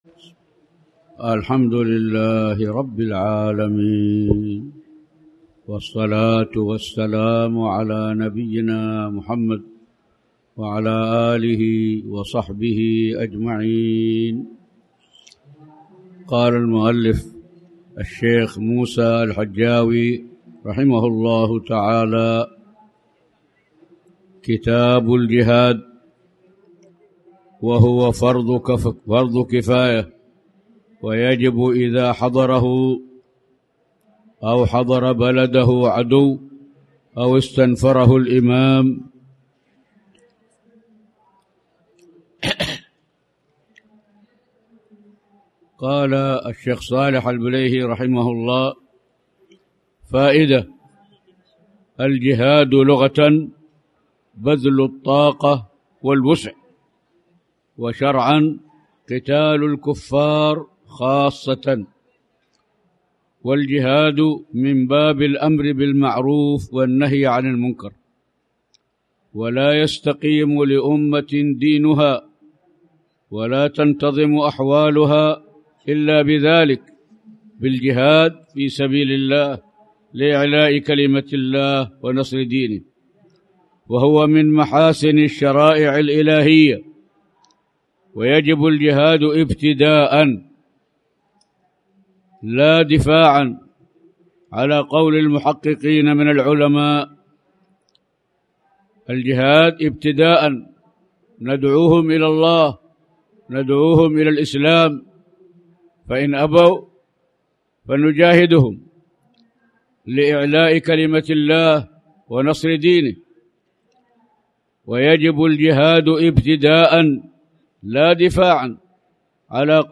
تاريخ النشر ١٣ محرم ١٤٣٩ هـ المكان: المسجد الحرام الشيخ